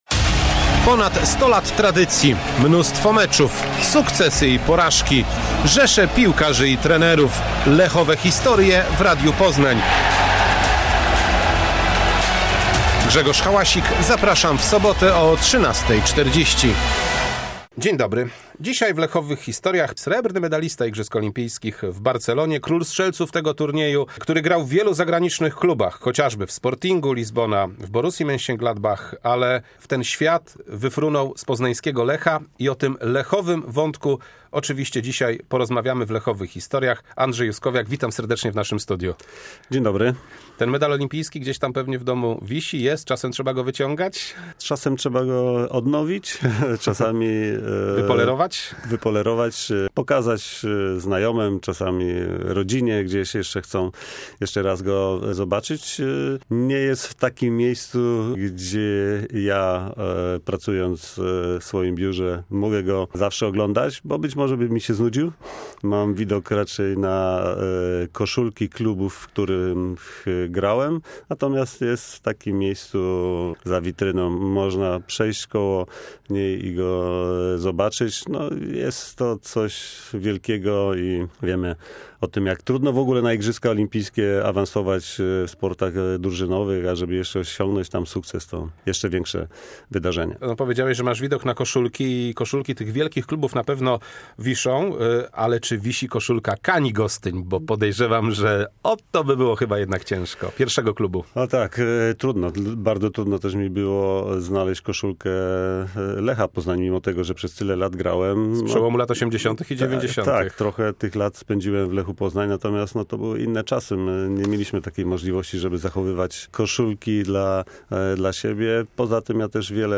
W 38. odcinku Lechowych historii pierwsza część rozmowy z Andrzejem Juskowiakiem, dwukrotnym mistrzem Polski z Kolejorzem, królem strzelców, wicemistrzem olimpijskim.